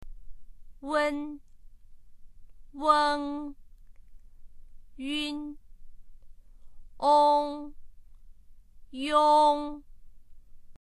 wen[-un] 　weng 　yun[-n]　　[-ong]　　yong[-iong]
wen-weng-yun-ong-yong.mp3